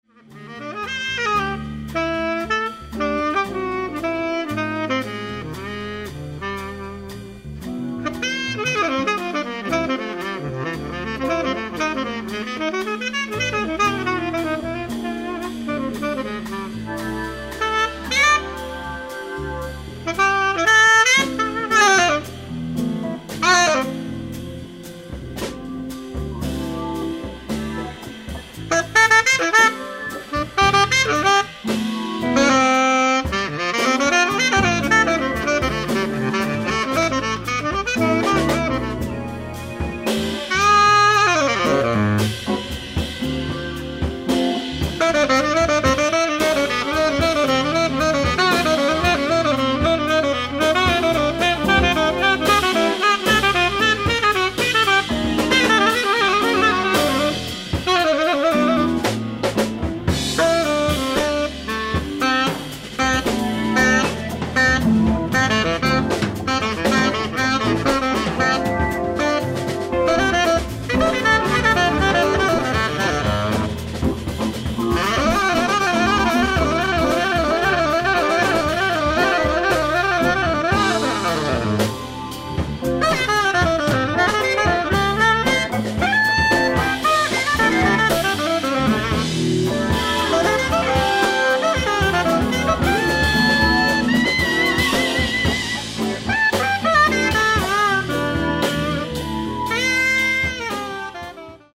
ライブ・アット・ヴィレッジ・ヴァンガード、ニューヨーク・シティー 10/22/1985(eary show)
サウンドボード級の極上オーディエンス音源盤！！
※試聴用に実際より音質を落としています。